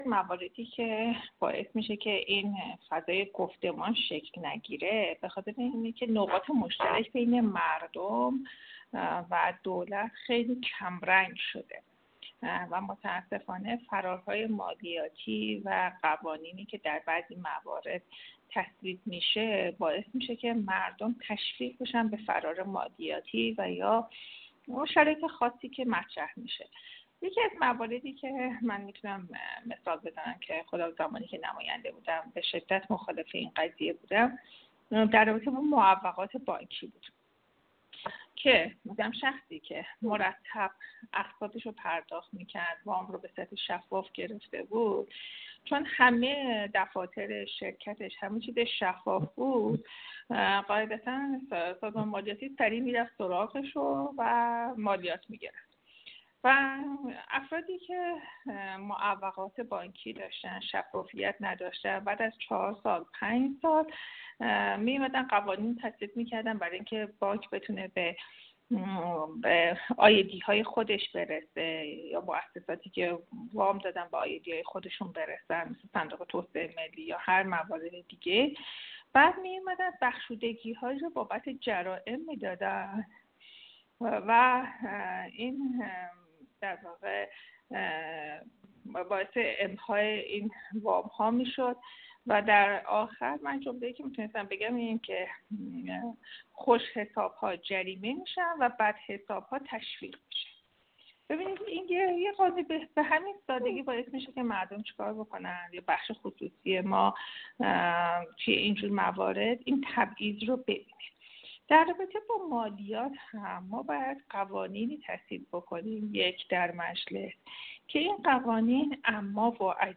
آقاپور علیشاهی در گفت‌وگو با ایکنا مطرح کرد:
عضو کمیسیون اقتصادی مجلس دهم تأکید کرد: از سوی دیگر نظام مالیاتی ایران از عدم به‌روز بودن رنج می‌برد و اطلاعات مالی افراد مربوط به سه یا چهار سال پیش است و این روزآمد نبودن سبب شده تا نظام مالیاتی کشور به اطلاعات غلط و غیرصحیح دسترسی داشته باشد، لذا ضروریست سامانه‌های مالیاتی کشور به سامانه‌های کشور وصل و از آنها اطلاعات دقیق را استعلام کند.